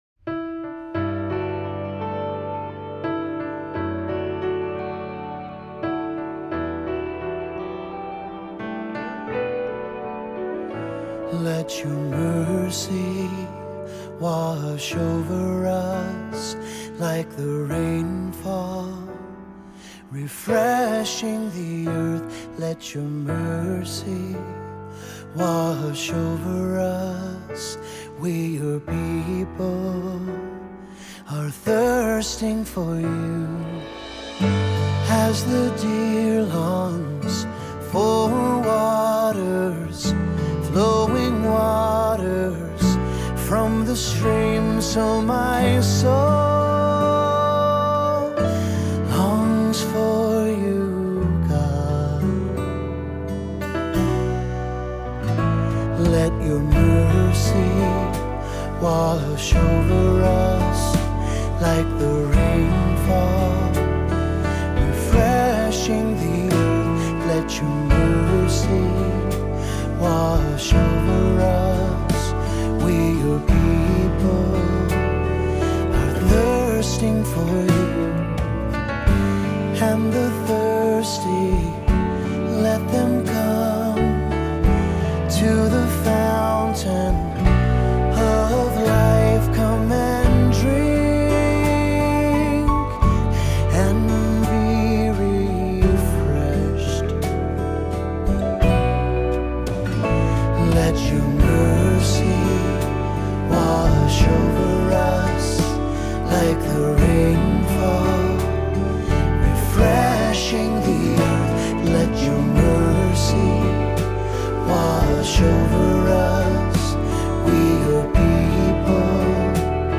Voicing: Assembly,SATB